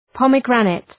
Προφορά
{‘pɒm,grænıt}